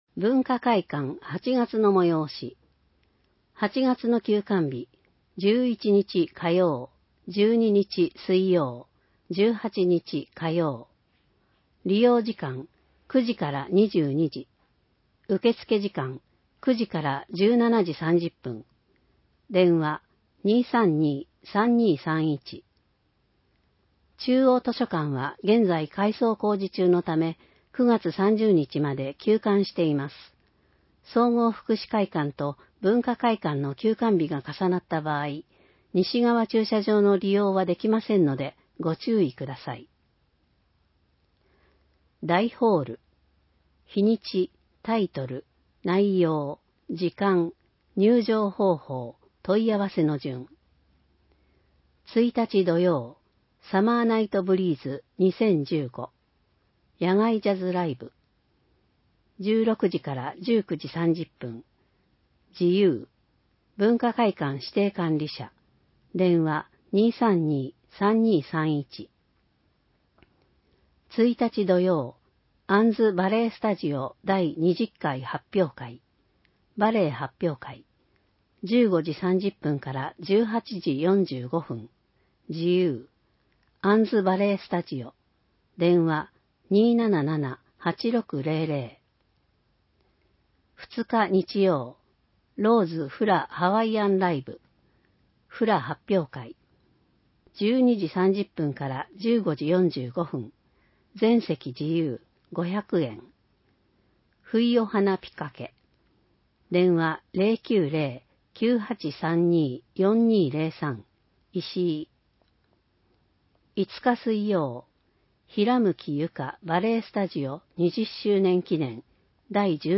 広報えびな 平成27年7月15日号（電子ブック） （外部リンク） PDF・音声版 ※音声版は、音声訳ボランティア「矢ぐるまの会」の協力により、同会が視覚障がい者の方のために作成したものを登載しています。